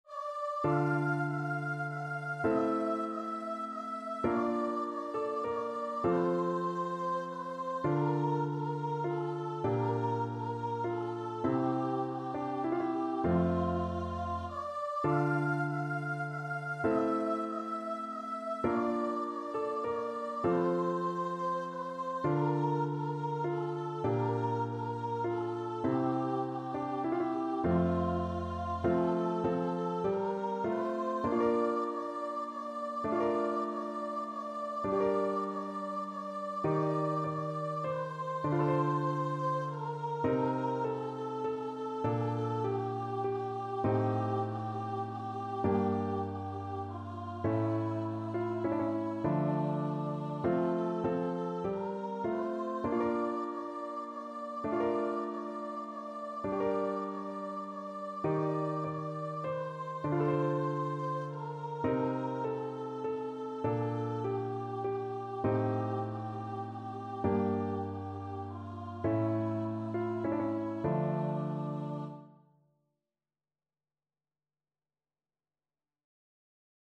Voice
D minor (Sounding Pitch) (View more D minor Music for Voice )
3/2 (View more 3/2 Music)
D5-F6
Classical (View more Classical Voice Music)